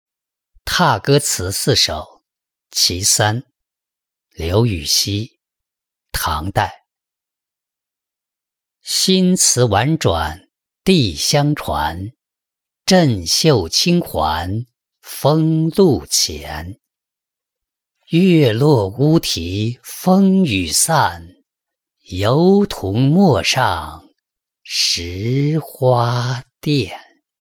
踏歌词四首·其三-音频朗读